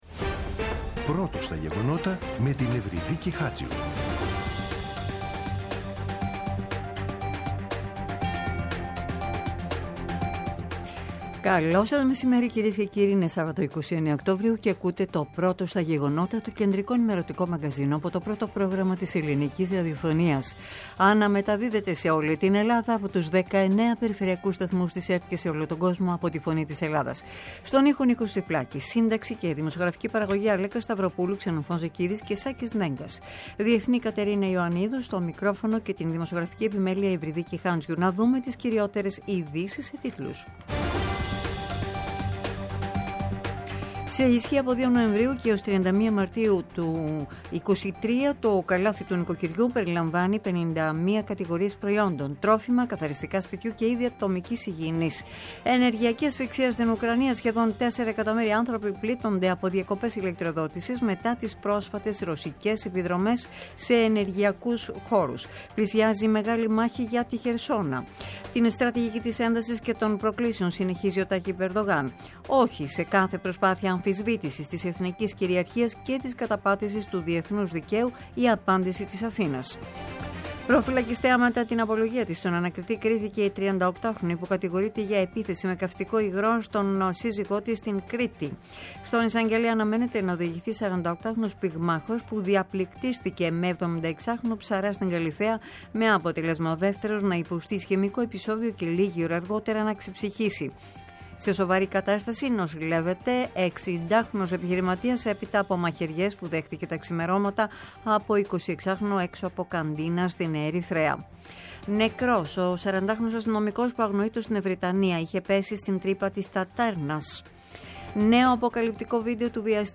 “Πρώτο στα γεγονότα”. Το κεντρικό ενημερωτικό μαγκαζίνο του Α΄ Προγράμματος στις 14.00. Με το μεγαλύτερο δίκτυο ανταποκριτών σε όλη τη χώρα, αναλυτικά ρεπορτάζ και συνεντεύξεις επικαιρότητας.